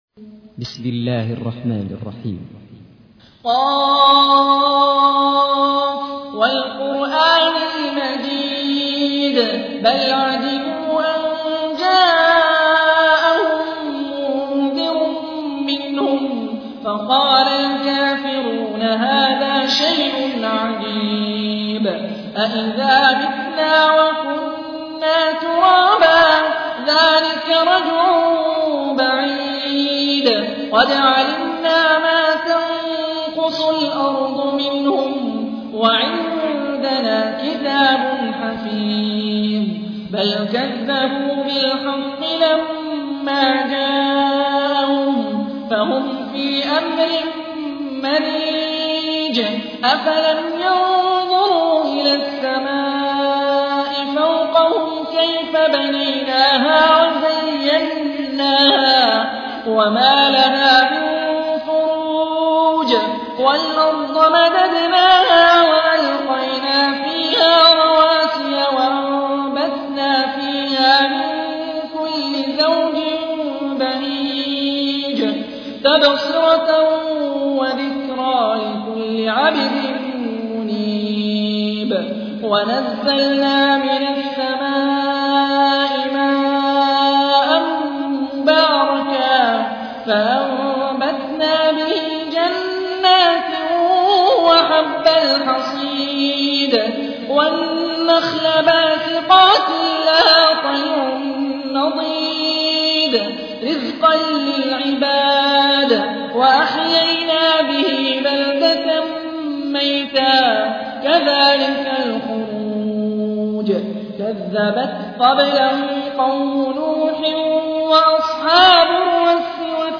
تحميل : 50. سورة ق / القارئ هاني الرفاعي / القرآن الكريم / موقع يا حسين